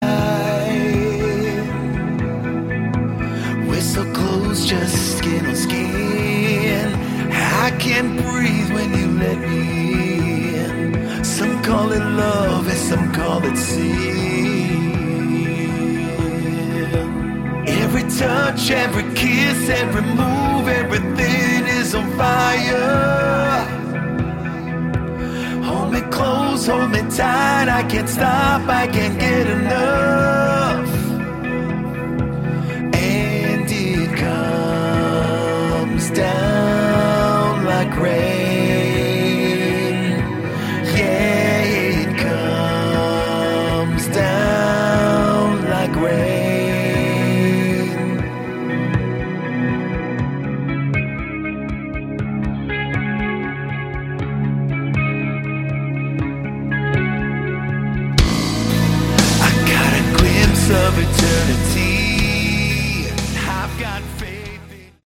Category: Melodic Rock
Vocals
Guitars, Bass, Keyboards, Backing Vocals
Drums